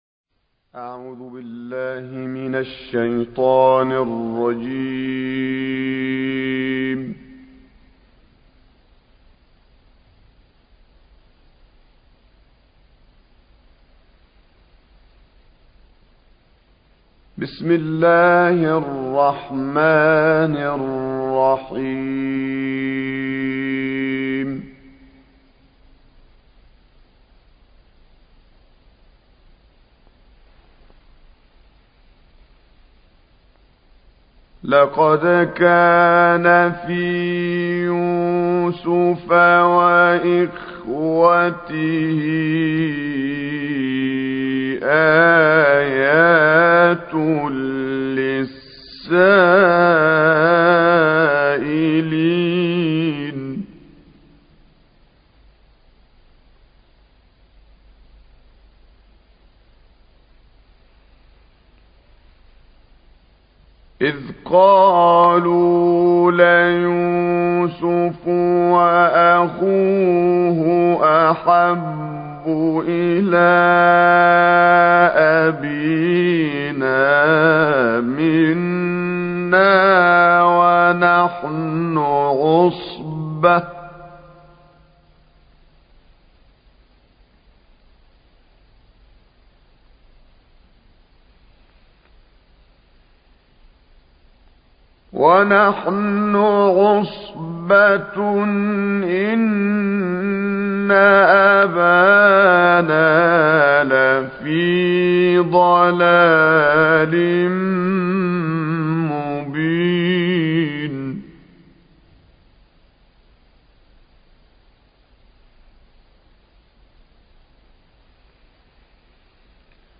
آیاتی از سوره یوسف
گروه شبکه اجتماعی: تلاوت آیات ابتدایی سوره یوسف را با صوت